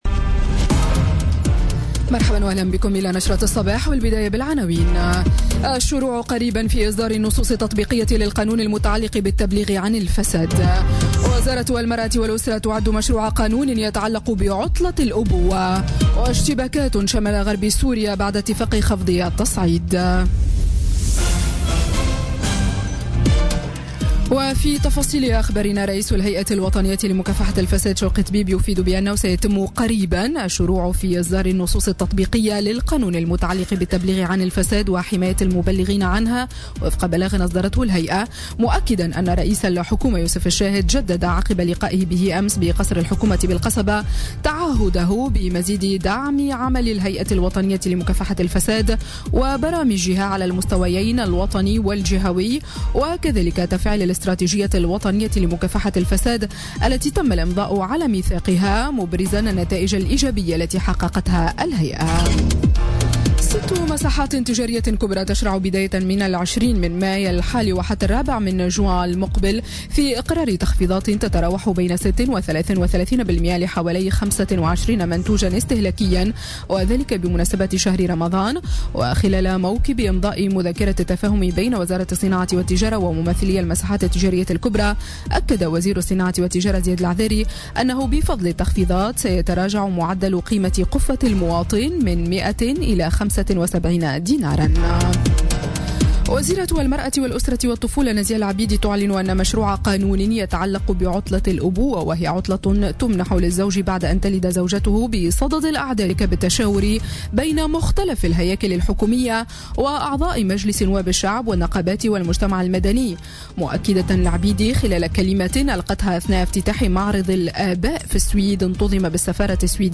نشرة أخبار السابعة صباحا ليوم السبت 6 ماي 2017